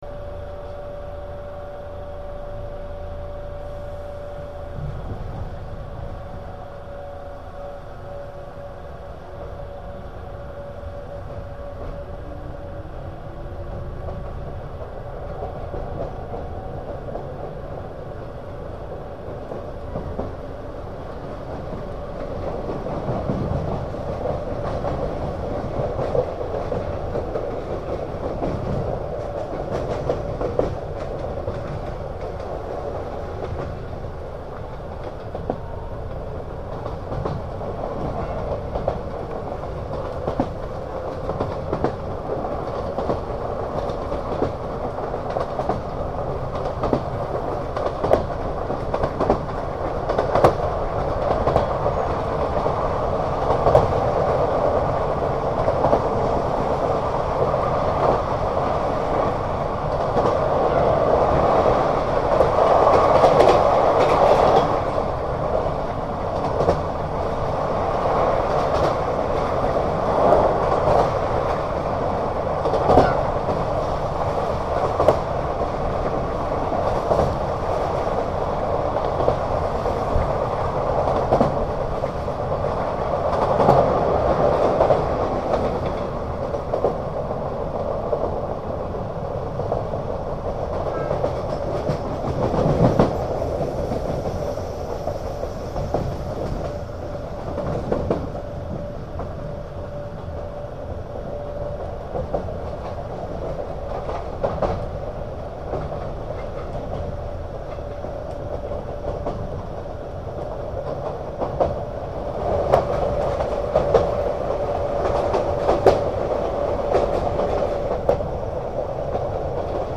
駅通過時の引込み線通過音が、往年の急行運用を思い出させます。
窓を開けて収録したため、音がクリアに聞こえる代わりに音割れが生じている部分もありますが、